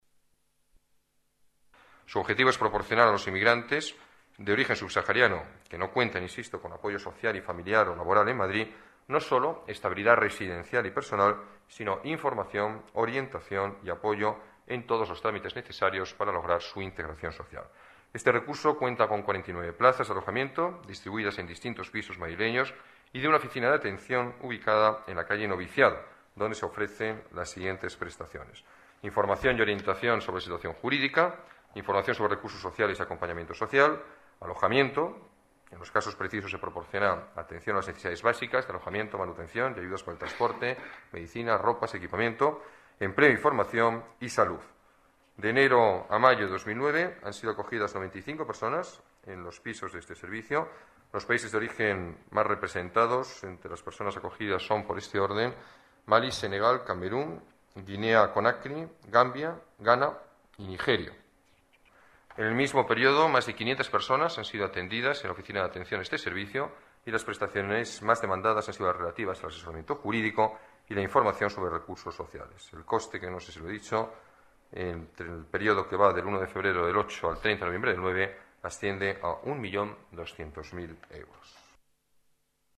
Nueva ventana:Declaraciones alcalde, Alberto Ruiz-Gallardón: ayuda subsaharianos